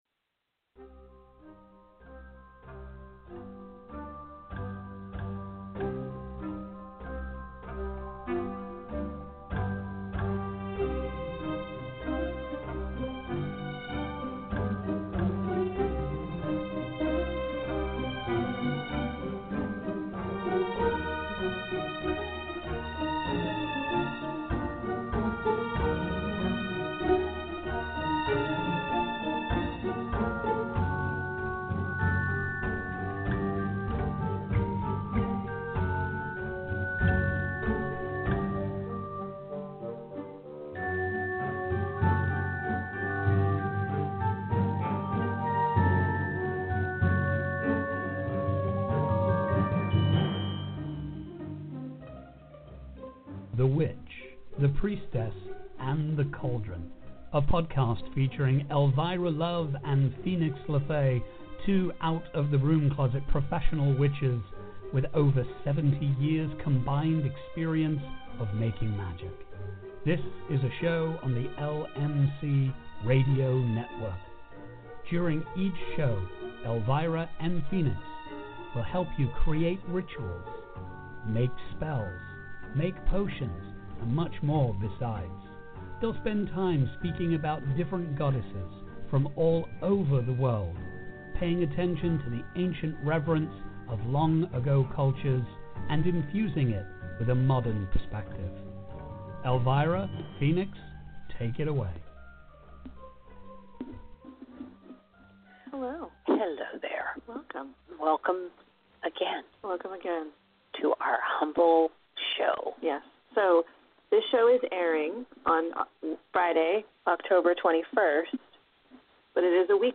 On The Witch, the Priestess, and the Cauldron podcast, professional witches